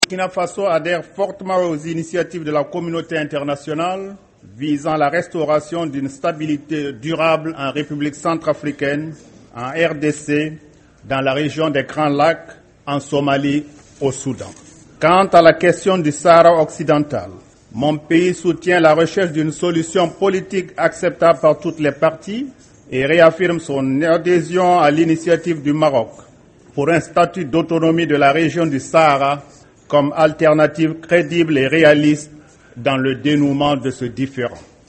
La deuxième journée de la 68e session de l'Assemblée générale aura permis d'exposer les diverses crises politico-militaires du continent.
Ecoutez le président Blaise Compaoré